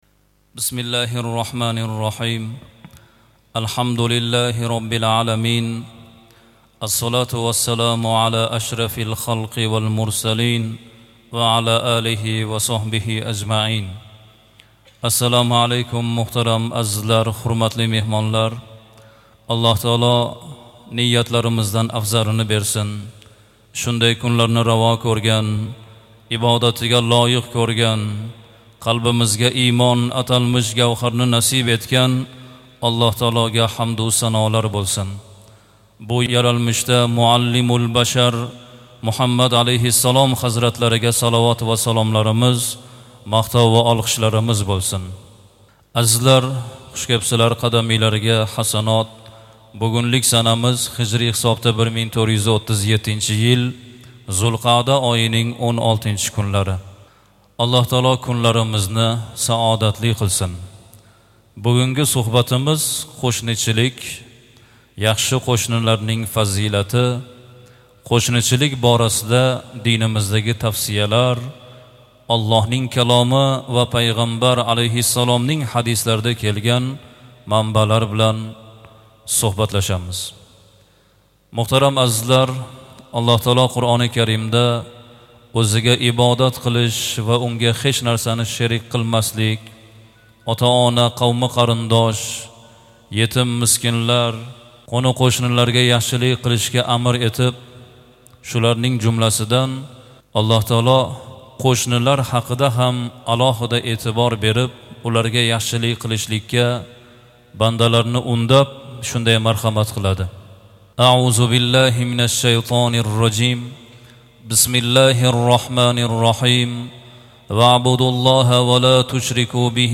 mp3, Қуръон, Ҳадислар, Маърузалар, Салавотлар